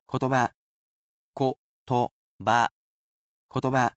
I will be sure to sound the main word out for you character by character.